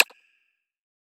sliderbar-notch.wav